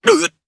Kibera-Vox_Damage_jp_01.wav